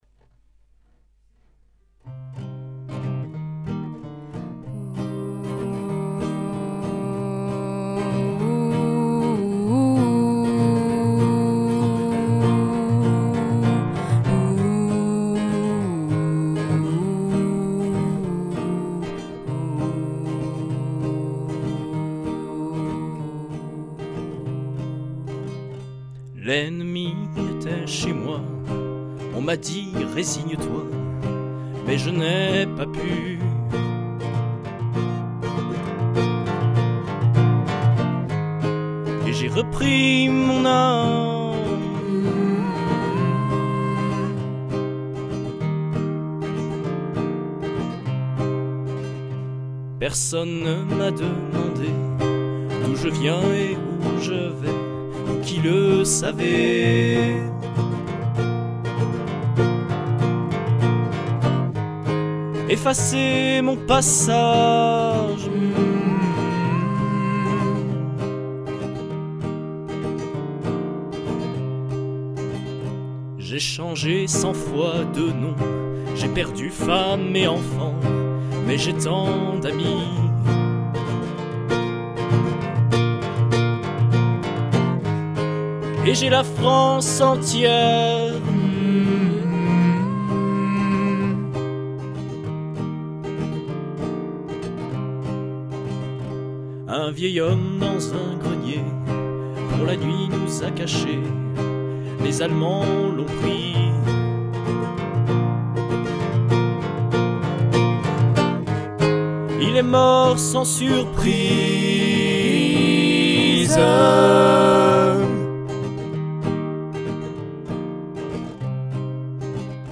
Chanteur
19 - 30 ans - Baryton